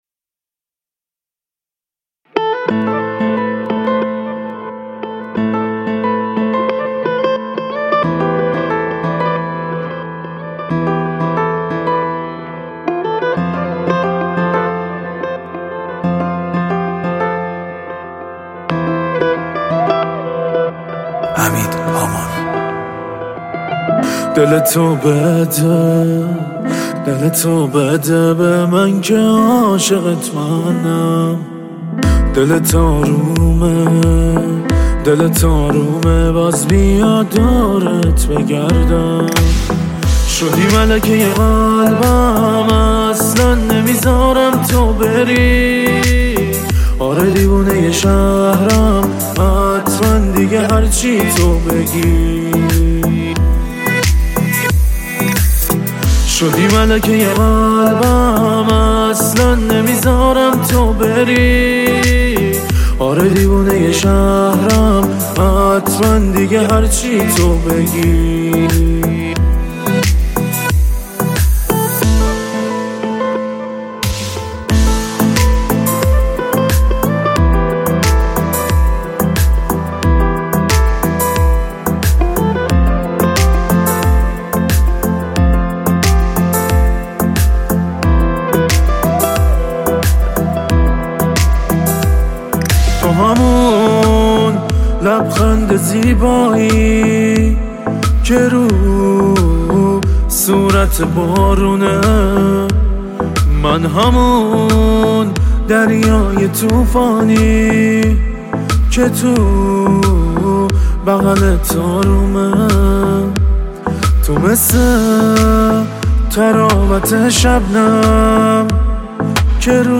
آهنگهای پاپ فارسی
کیفیت بالا